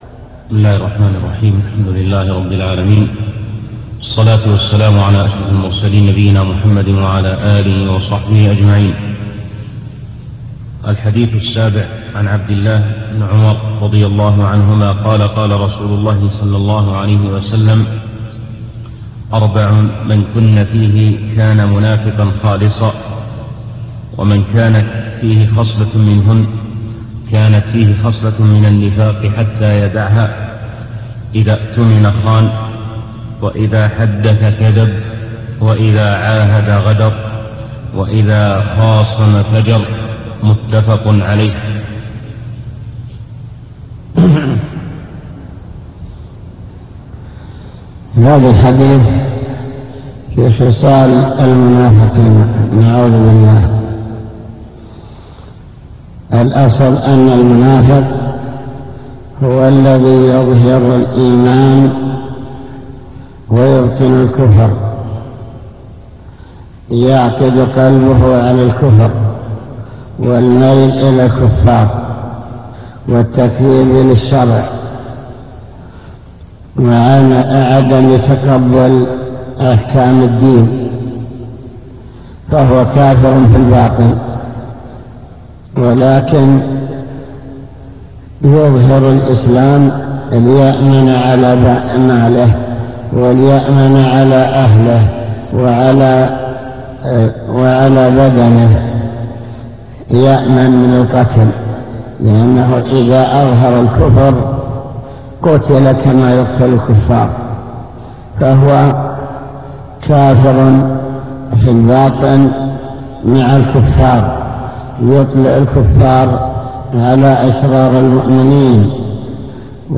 المكتبة الصوتية  تسجيلات - كتب  شرح كتاب بهجة قلوب الأبرار لابن السعدي شرح حديث أربع من كن فيه كان منافقا خالصًا